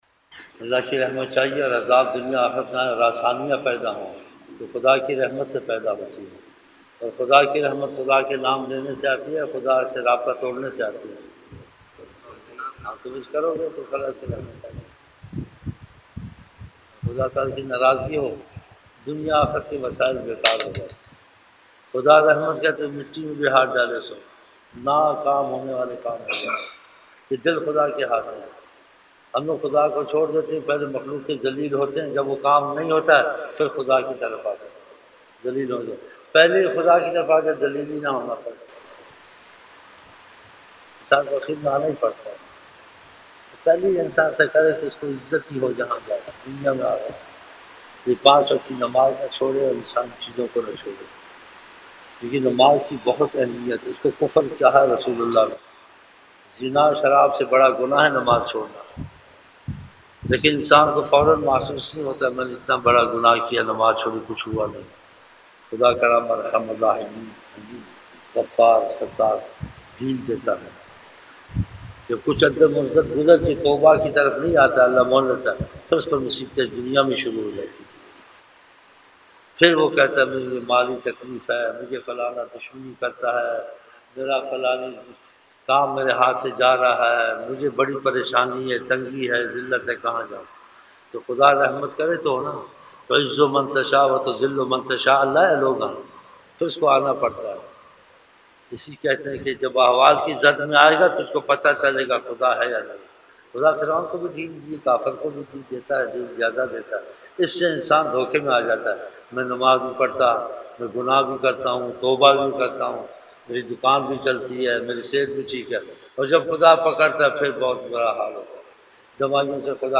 ظہر شروع کی محفل